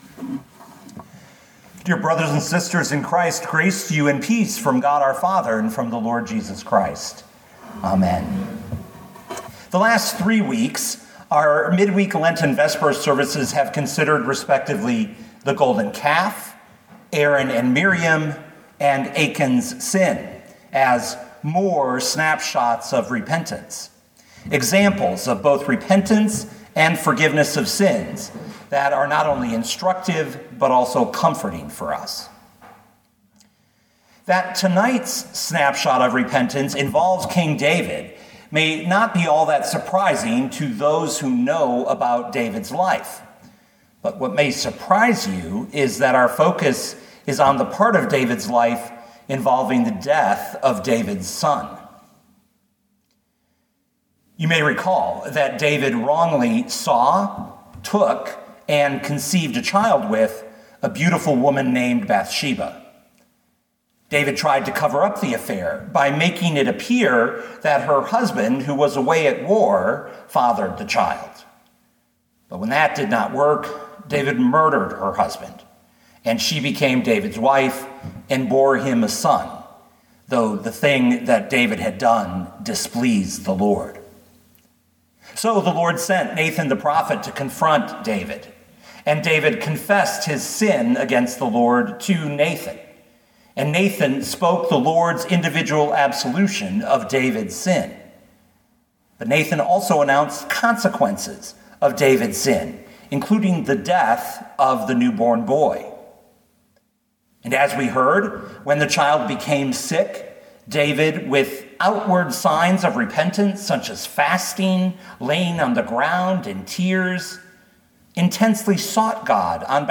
2022 2 Samuel 12:15b-23 Listen to the sermon with the player below, or, download the audio.